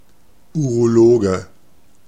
Ääntäminen
IPA: /ˌuʁoˈloːɡə/